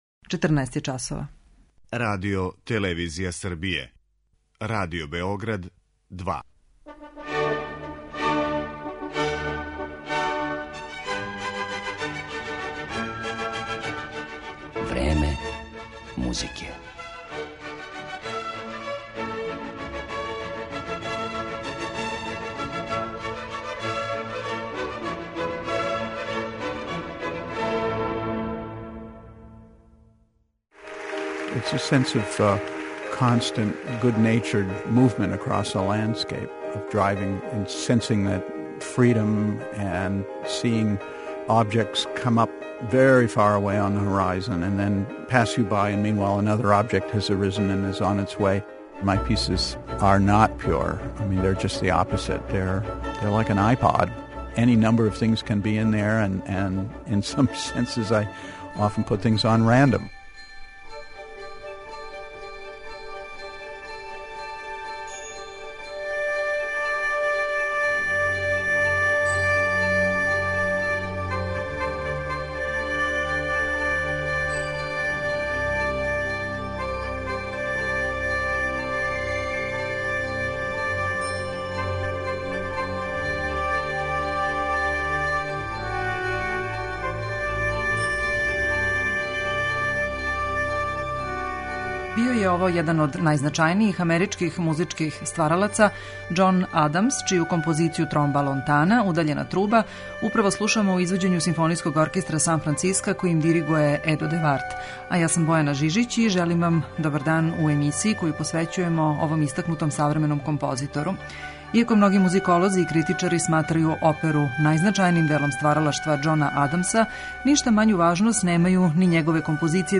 инструменталну музику